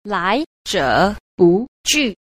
10. 來者不拒 – lái zhě bù jù – lai giả bất cự (không từ một ai, ai đến cũng nhận)
lai_zhe_bu_ju.mp3